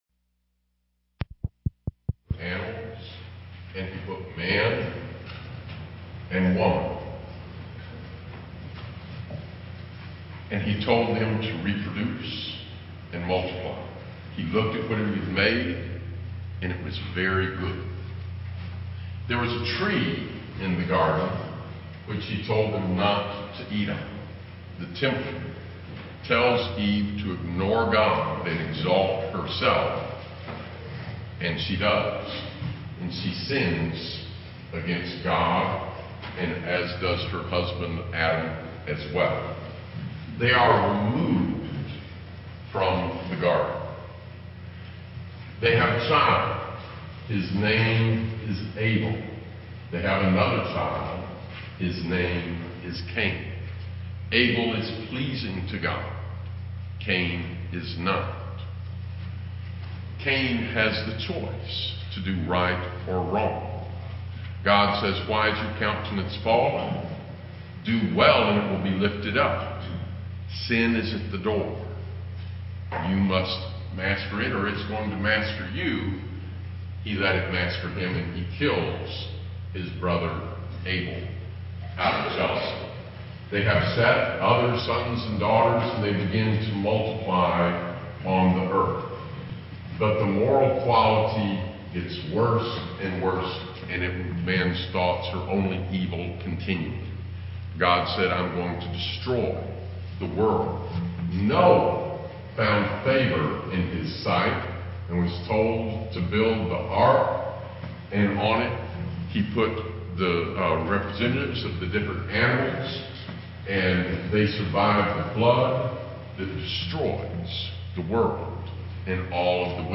A recording error removed the preamble: this is the intro to a 3 part series